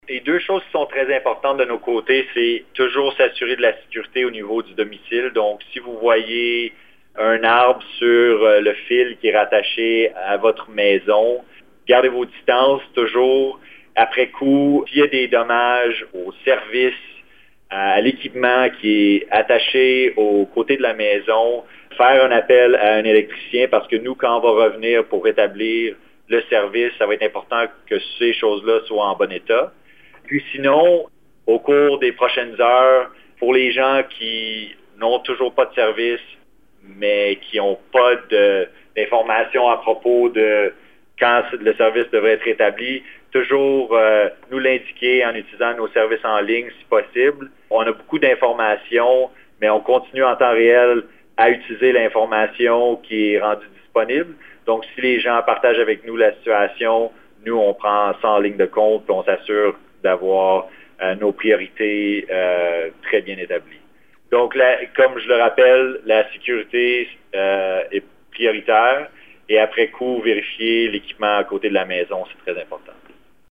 CJFO-HYDRO-Ottawa-que-faire.mp3